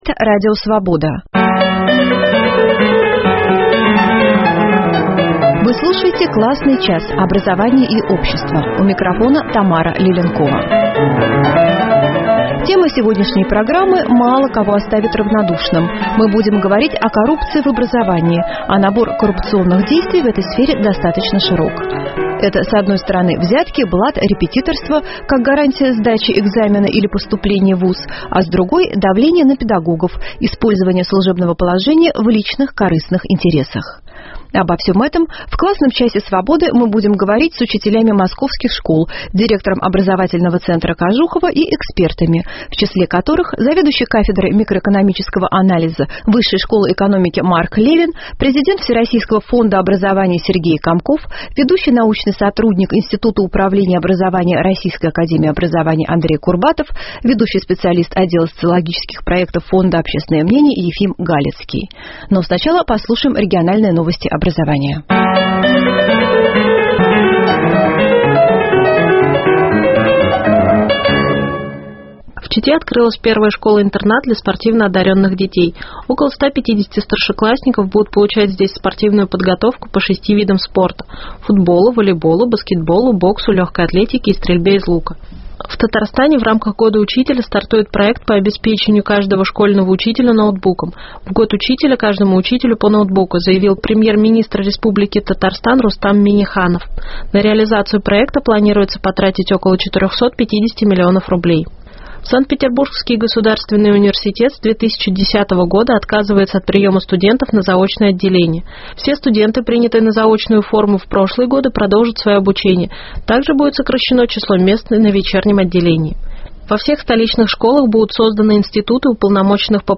Коррупция в образовании. Незаконные поборы, использование служебного положения, ЕГЭ, как средство борьбы с коррупцией и будущее без профессионалов – эти темы в «Классном часе Свободы» обсуждают эксперты и пострадавшие, учителя и профессора.